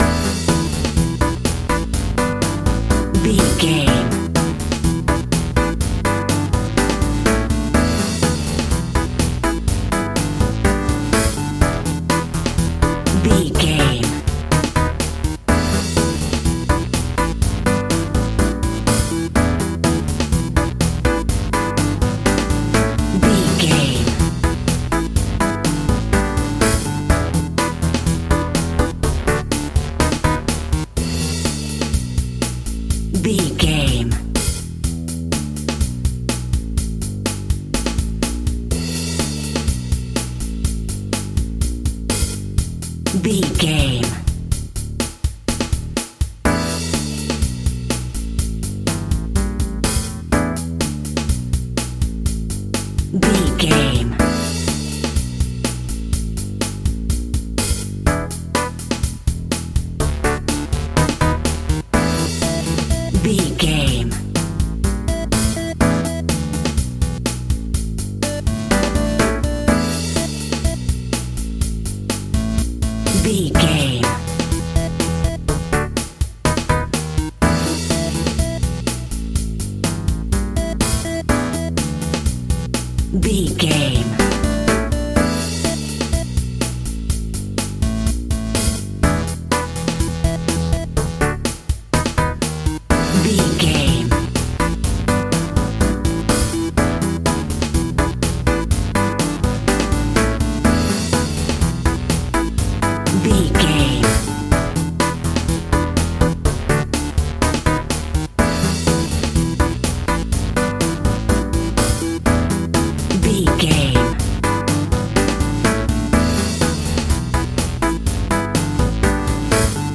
Ionian/Major
Bubblegum pop
Teen pop
cheesy
electronic pop
dance pop
pop instrumentals
light
drums
bass
keyboards
guitars